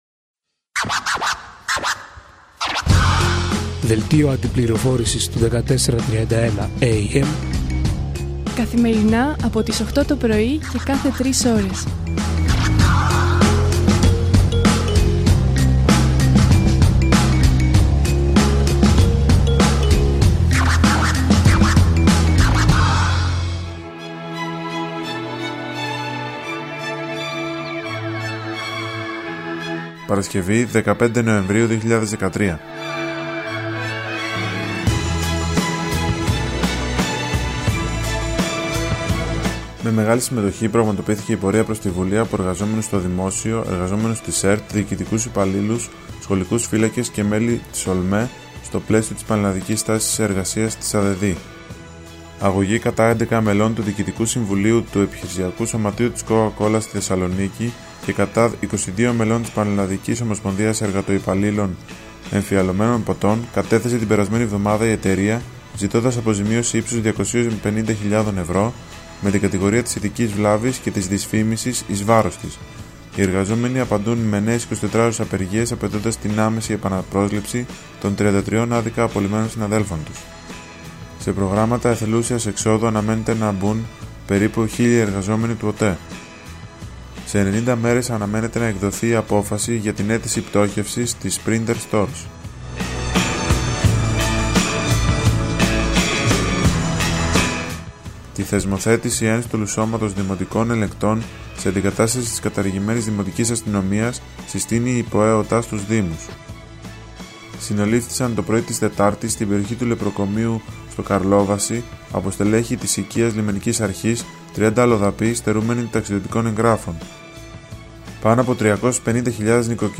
Δελτίο Αντιπληροφόρησης